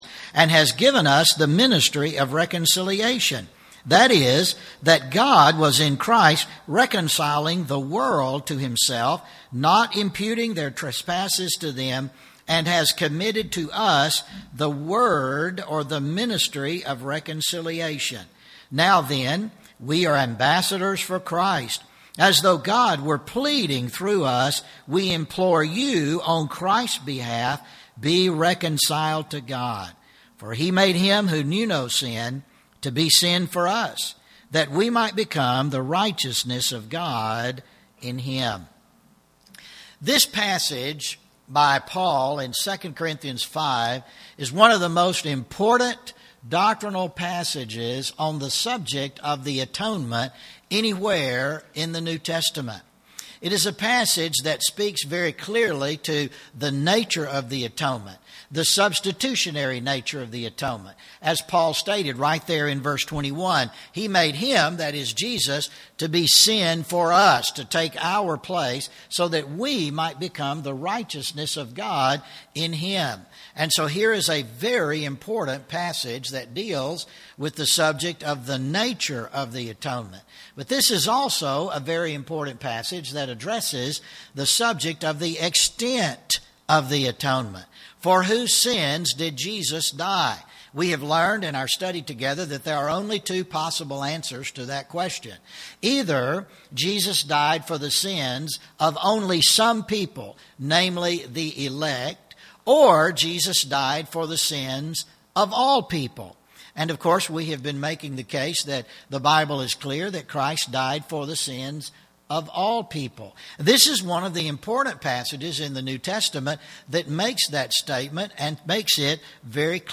Service Type: Special event